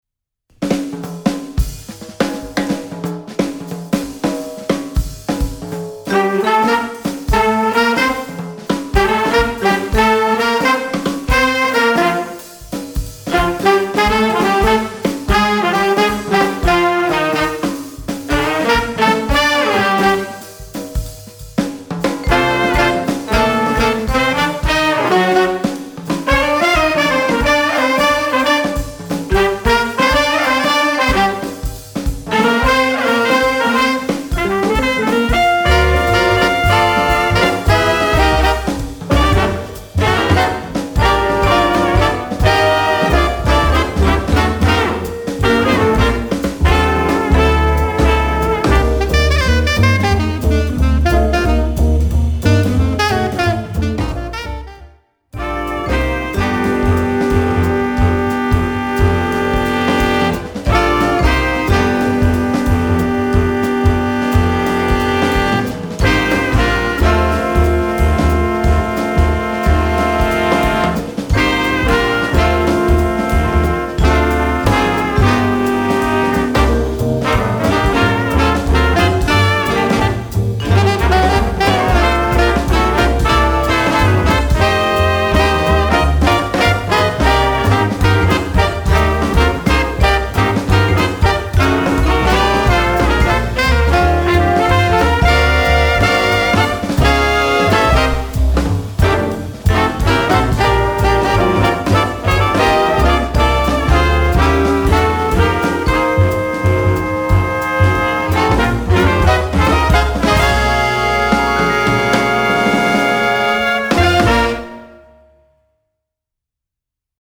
Voicing: Little Big Band